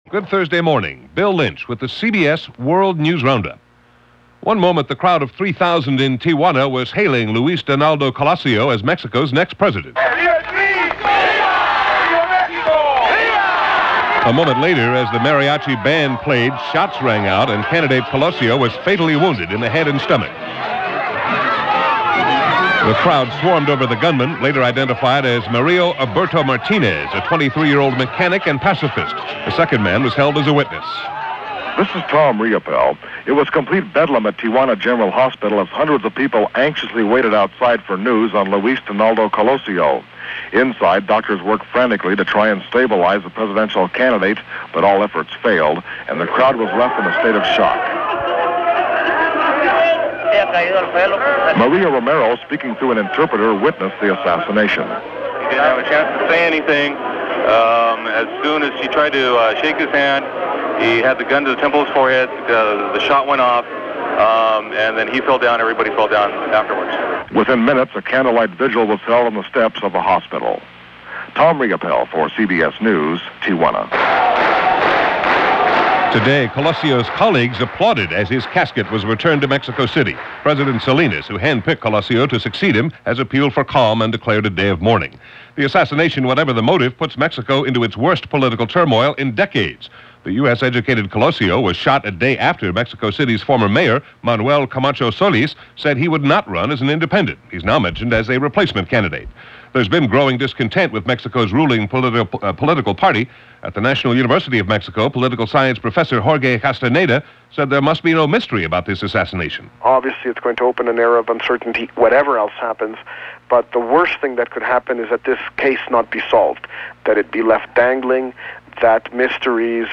[laterpay_premium_download target_post_id=”13339″ heading_text=”Download For $1.99:” description_text=”March 25, 1994 – CBS World News Roundup – Gordon Skene Sound Collection” content_type=”link”]